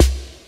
DRUMMACHINESNR.wav